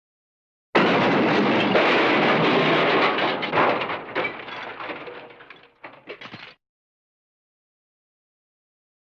Crash crash ding tinkle tinkle
crash-crash-ding-tinkle-tinkle.mp3